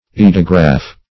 Search Result for " eidograph" : The Collaborative International Dictionary of English v.0.48: Eidograph \Ei"do*graph\, n. [Gr. e'i^dos form + graph.] An instrument for copying drawings on the same or a different scale; a form of the pantograph.